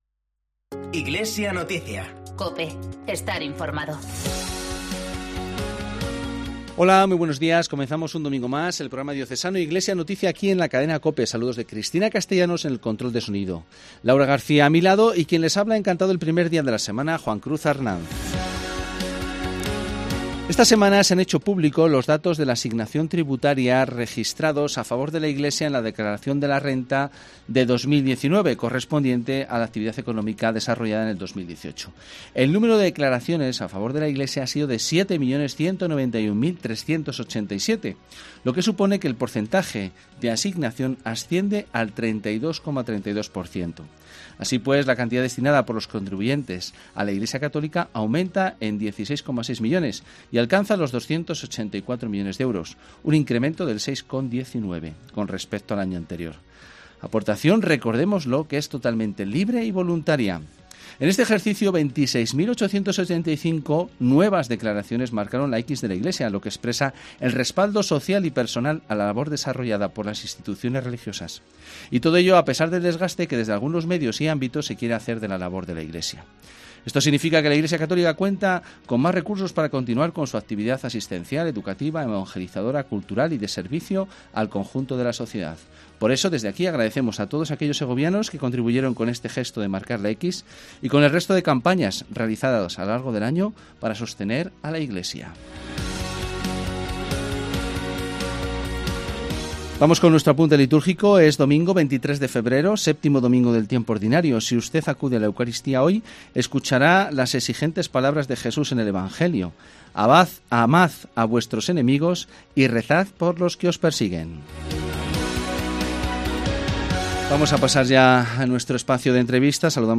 PROGRAMA RELIGIOSO